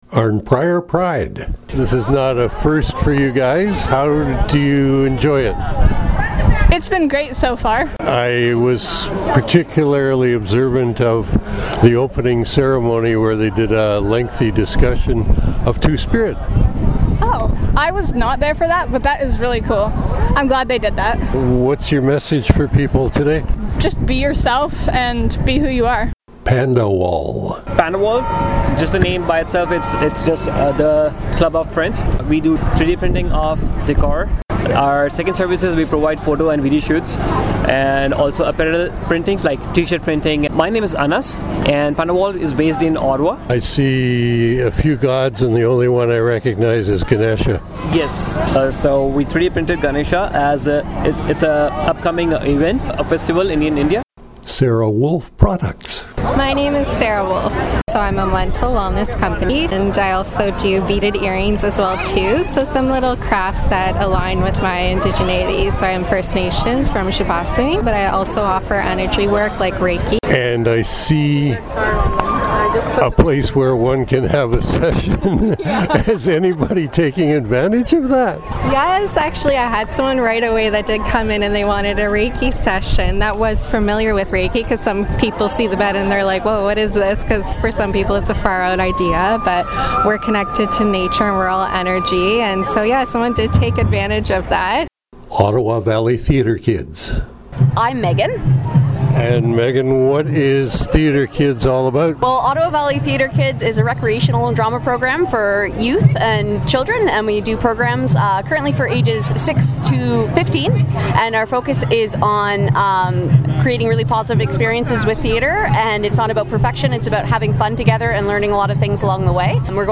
For those of us hungry for information, your myFM reporter indulged in a series of interviews with exhibitors and vendors, which is assembled into the audio collage below.